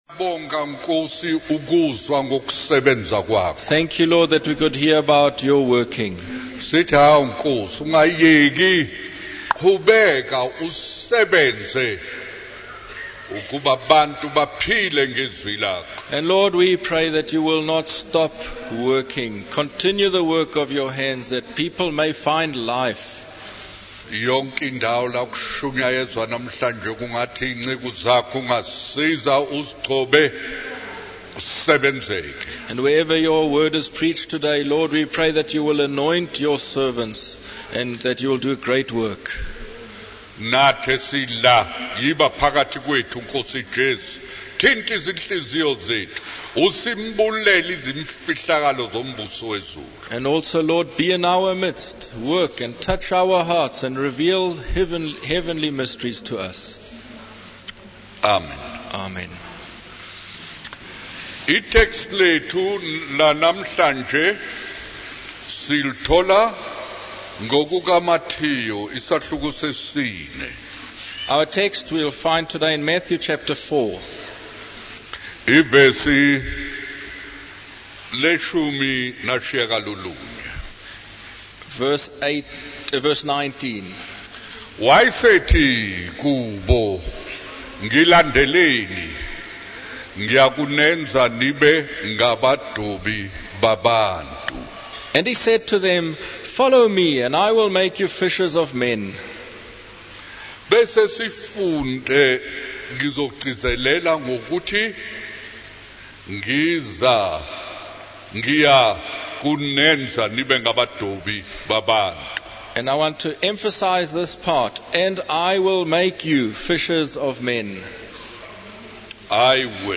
In this sermon, the preacher emphasizes the importance of hiding and not being seen.